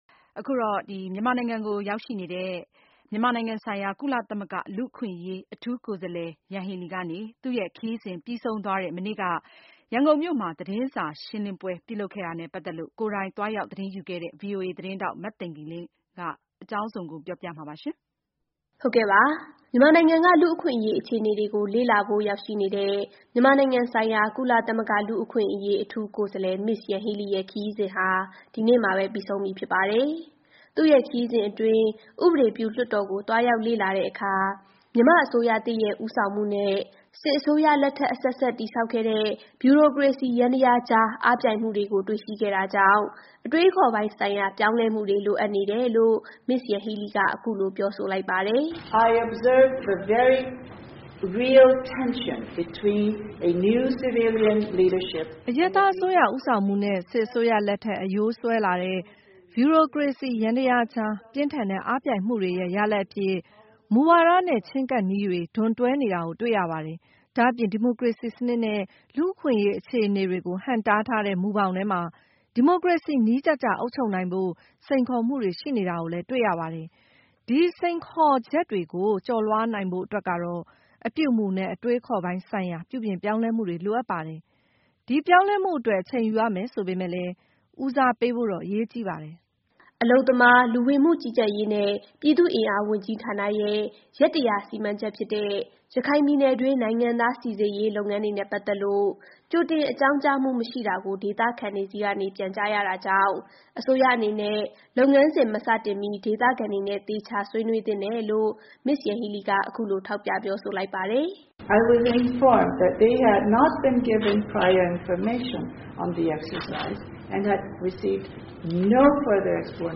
Ms. Yanghee Lee ရဲ့ ၁၂ ရက်ကြာ မြန်မာပြည်ခရီးစဉ်အပြီး ကျင်းပတဲ့ သတင်းစာရှင်းလင်းပွဲ။